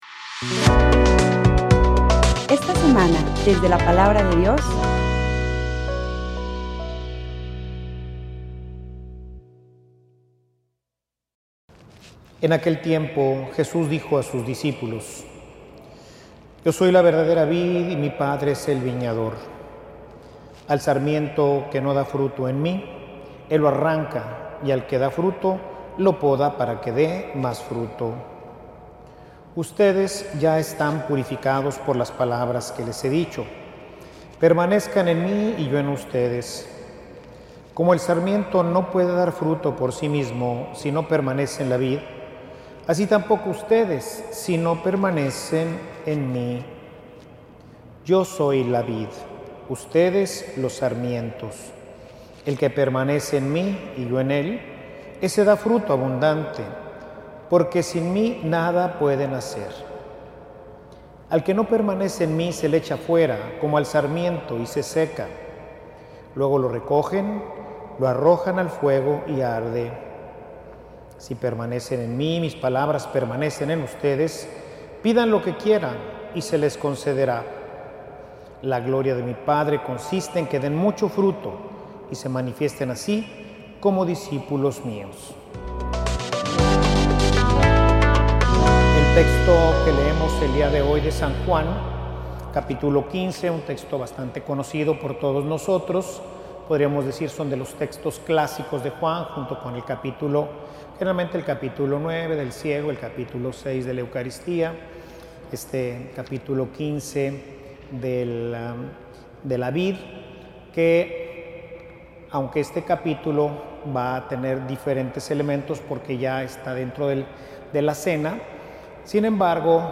Homilia_Revision_de_frutos-La_amabilidad.mp3